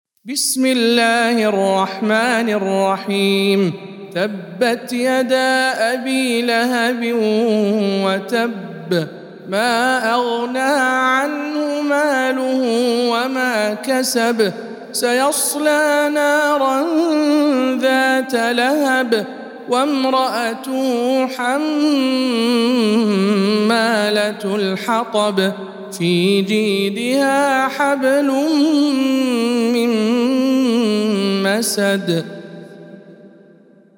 سورة المسد- رواية ابن جماز عن أبي جعفر